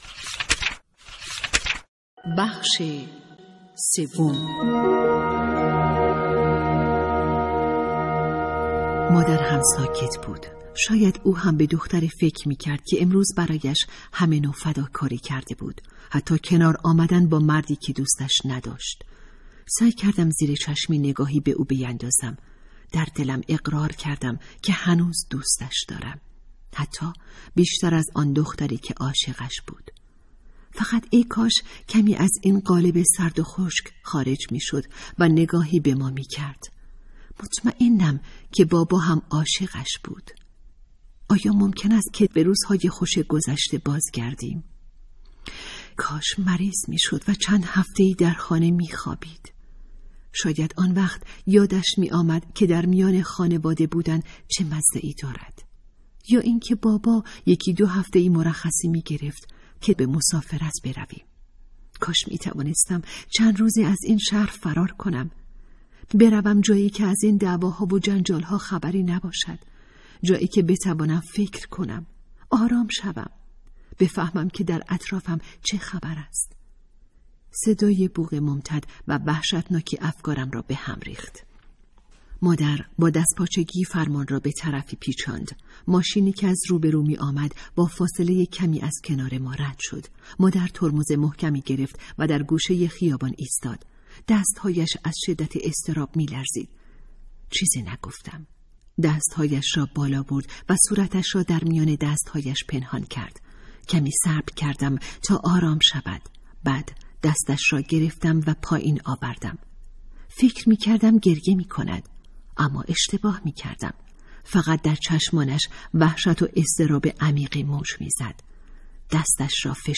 کتاب صوتی دختران آفتابیک داستان بلند درباره دختران ایران